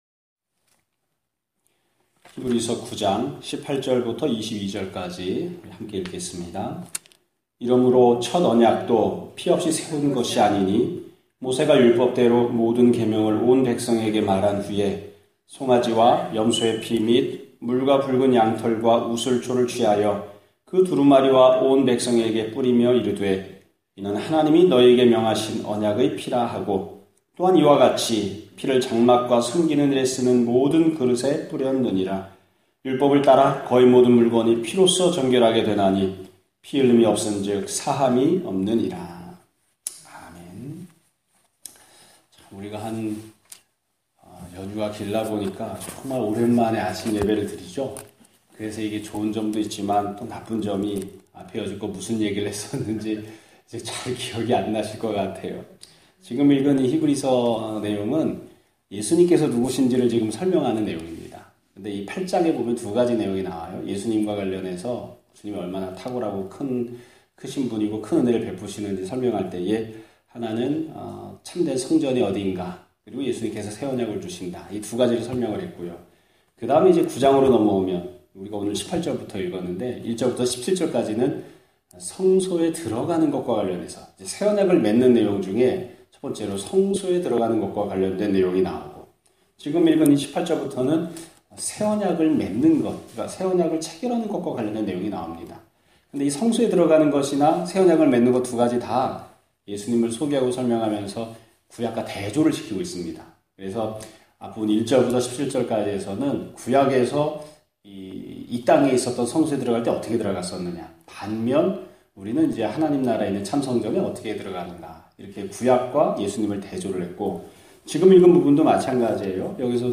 2017년 10월 10일(화요일) <아침예배> 설교입니다.